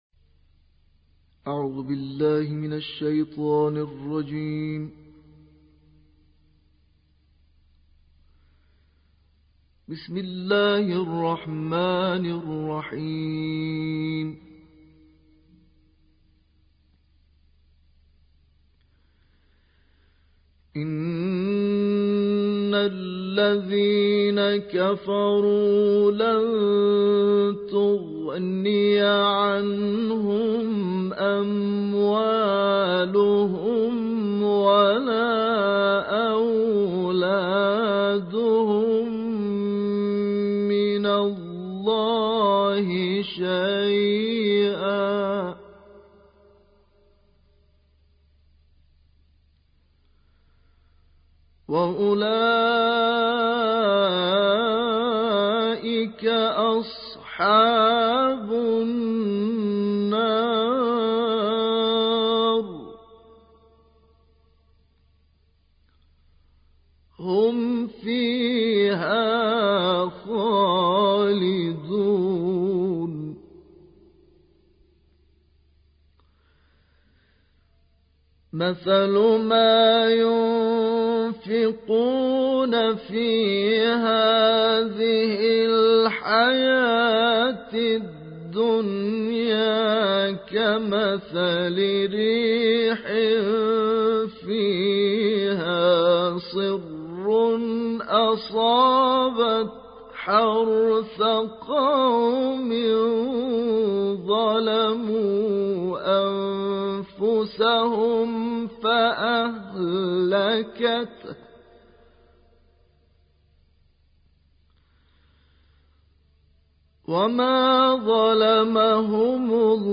دانلود قرائت سوره آل عمران آیات 116 تا 132